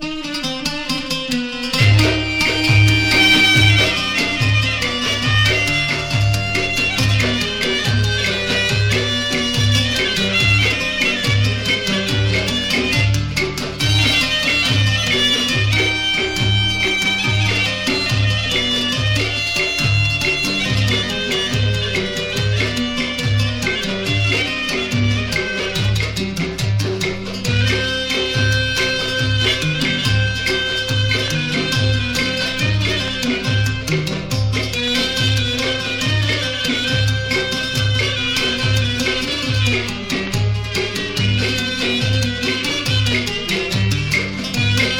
独特とも神秘的とも思えるエキゾティシズムをまとった魅力的な楽曲が緩急自在な演奏によりうねります。
※盤質によるプチプチノイズ有
World, Middle East　USA　12inchレコード　33rpm　Stereo
盤擦れ　プチプチノイズ